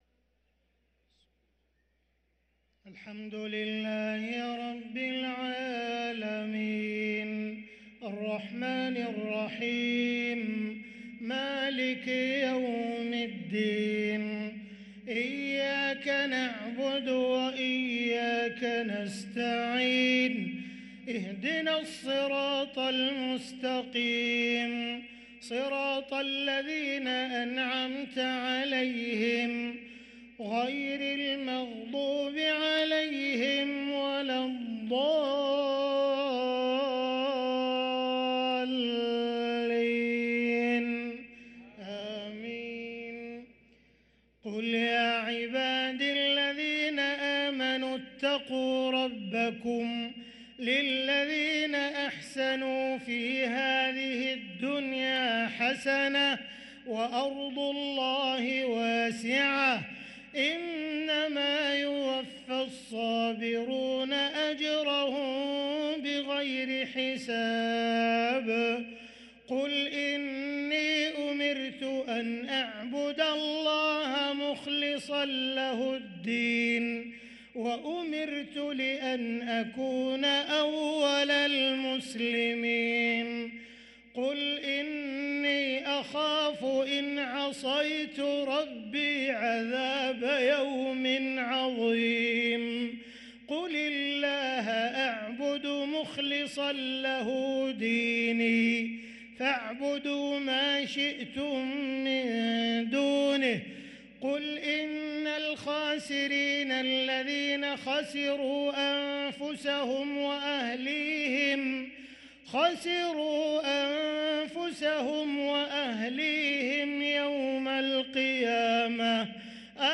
صلاة العشاء للقارئ عبدالرحمن السديس 18 جمادي الآخر 1444 هـ